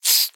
Sound / Minecraft / mob / silverfish / say1.ogg